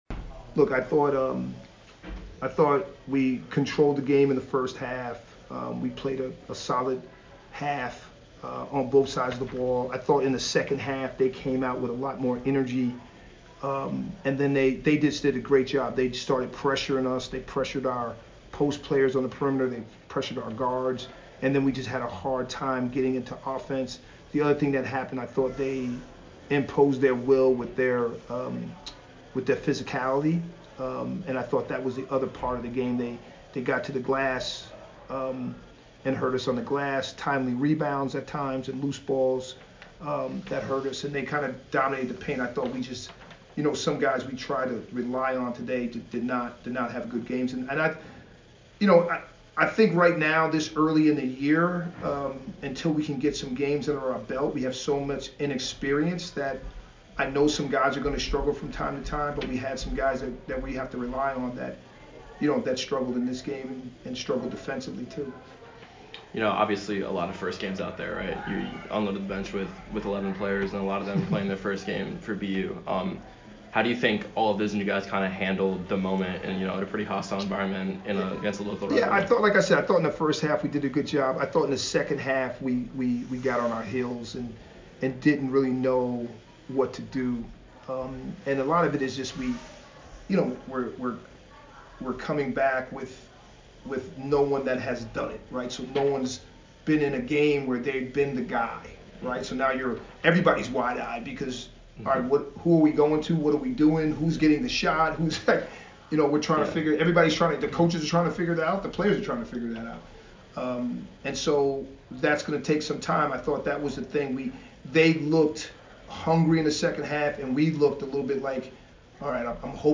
Northeastern Postgame Interview